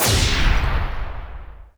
skins/Skins/jhfufk/EXTRAS/SDVX Slider WOOSH sounds/normal-sliderslide.wav at d8100f6f133229b11859b1452b3bee533aa8c94a
normal-sliderslide.wav